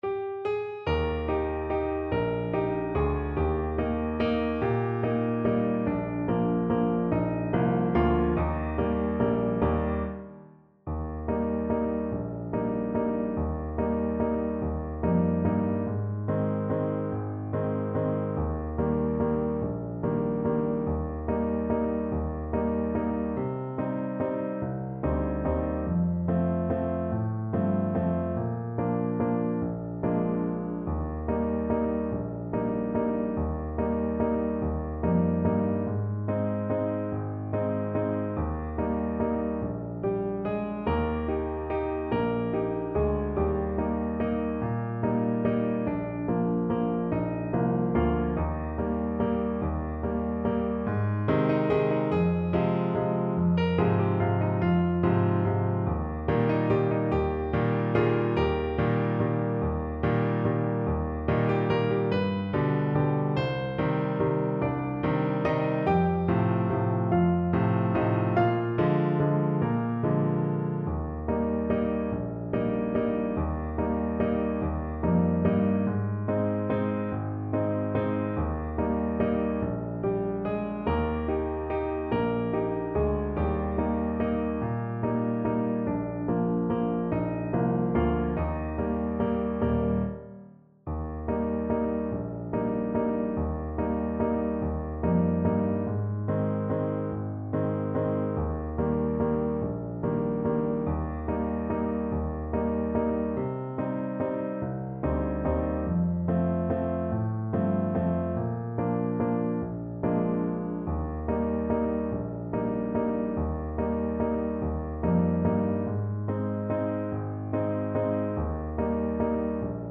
Alto Saxophone
One in a bar .=c.48
3/4 (View more 3/4 Music)
Bb4-C6
Classical (View more Classical Saxophone Music)